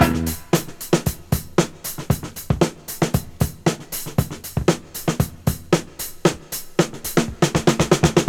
• 116 Bpm Fresh Drum Groove E Key.wav
Free drum beat - kick tuned to the E note. Loudest frequency: 1850Hz
116-bpm-fresh-drum-groove-e-key-EnK.wav